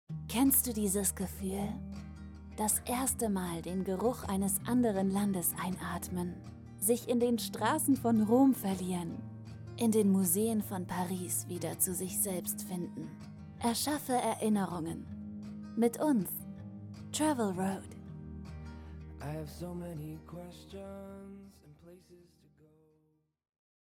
Alemão (Suíça)
Autêntico
Cativante
Nervoso